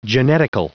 Prononciation du mot genetical en anglais (fichier audio)
Prononciation du mot : genetical